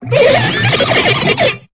Cri de Coatox dans Pokémon Épée et Bouclier.